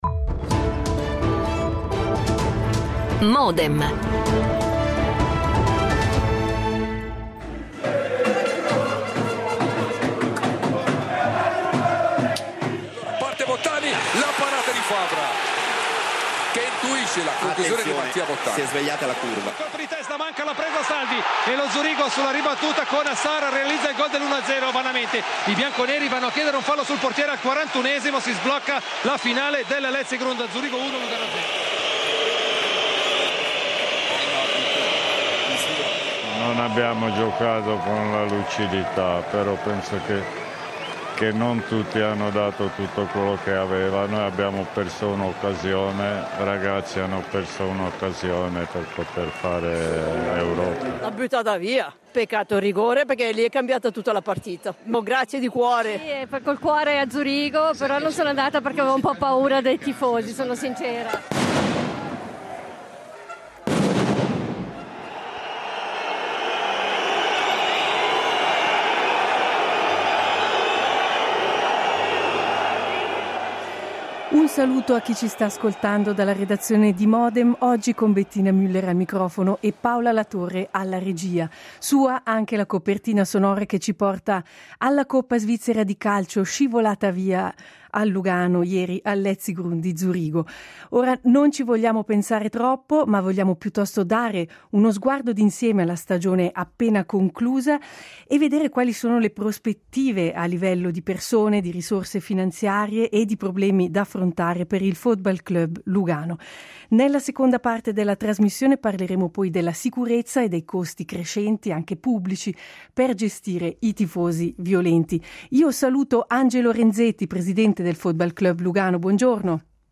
L'attualità approfondita, in diretta, tutte le mattine, da lunedì a venerdì